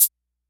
Closed Hats
SBV_V12_Hat_025.wav